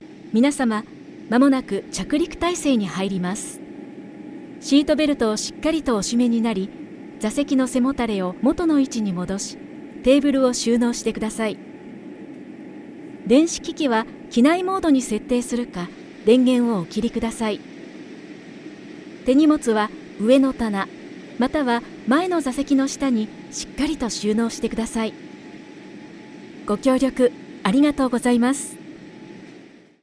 Ankündigungen